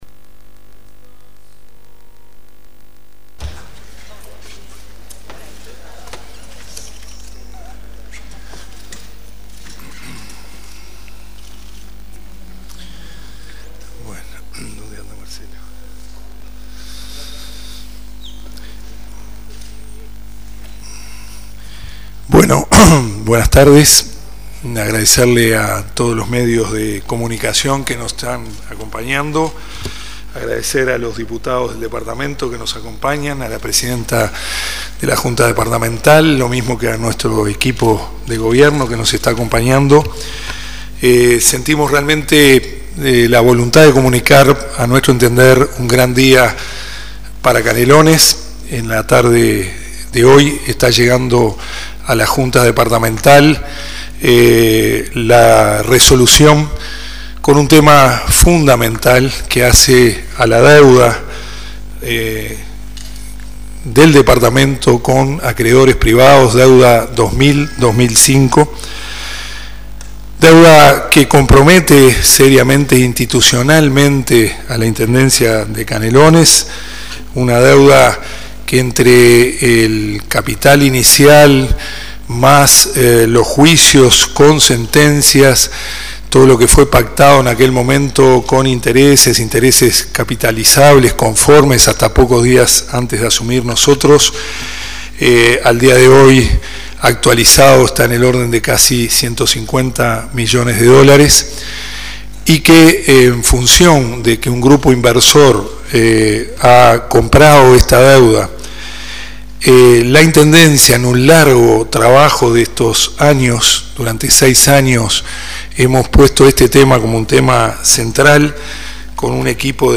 El Intendente de Canelones Dr. Marcos Carámbula, acompañado por el Secretario General Prof. Yamandú Orsi, la Prosecretaria Sra. Loreley Rodríguez y los integrantes de su gabinete, afirmó que con este acuerdo se inicia una nueva etapa en la comuna Canaria. El anuncio oficial se realizó el lunes 22 de agosto en la sala de sesiones del Congreso Nacional de Intendentes.
Conferencia_Dr._Marcos_Carambula.mp3